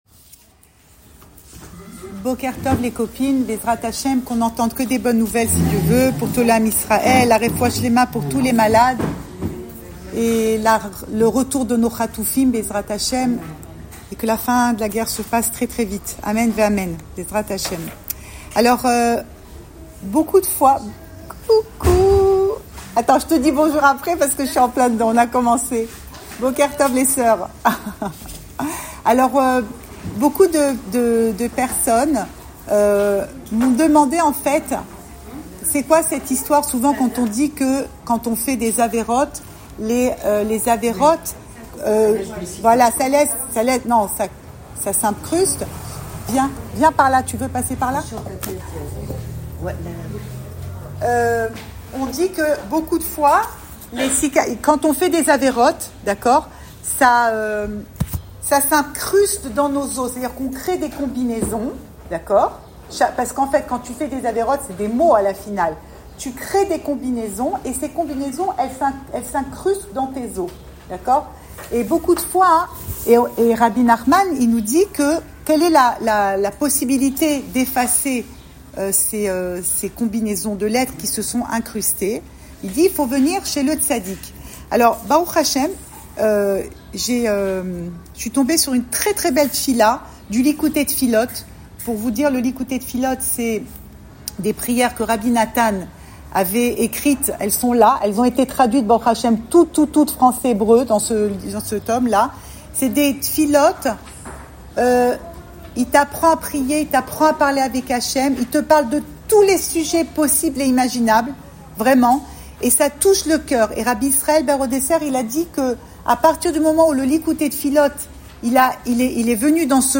Cours audio Le coin des femmes Le fil de l'info Pensée Breslev - 21 mai 2025 23 mai 2025 Rien n’est acquis. Enregistré à Tel Aviv